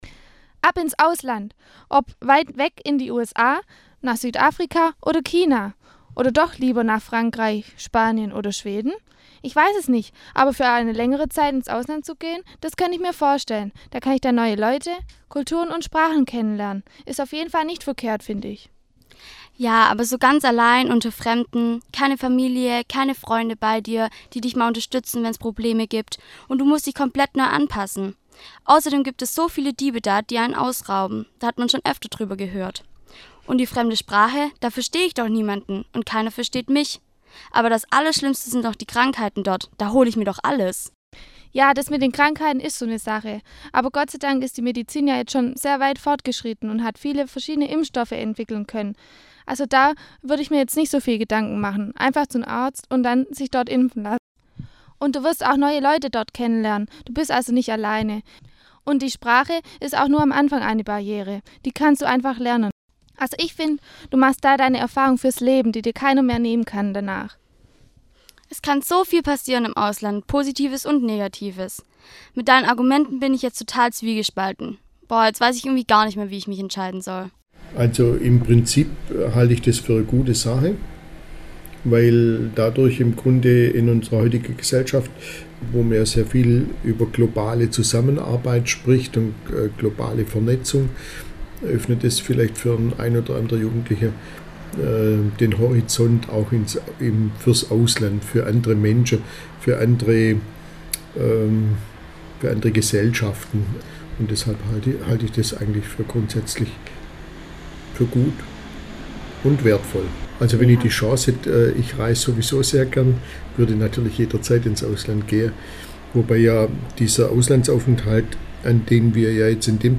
In vier Umfragen kommen diverse Geschichten und Erfahrungen zu dieser Orientierungsphase im Leben zur Sprache.